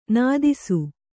♪ nādisu